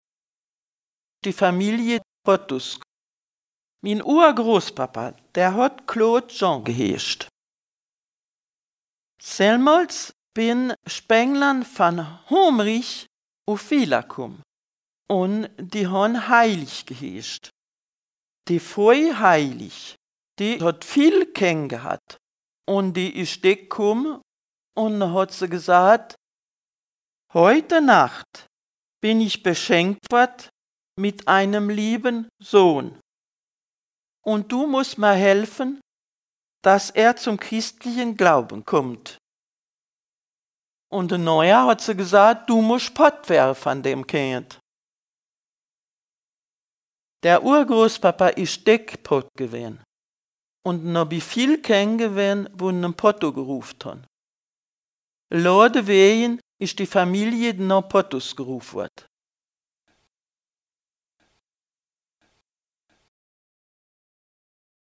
Contes et récits en ditsch enregistrés dans les communes de Racrange, Vallerange, Bérig-Vintrange, Harprich, Eincheville, Viller-Béning, Viller, Viller-Boustroff et de Boustroff.